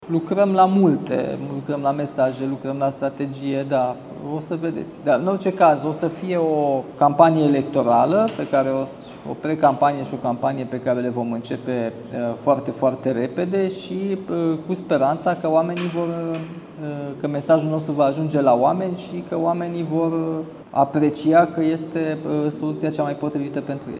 Primarul Nicușor Dan spune că lucrează deja la mesajele pentru campanie: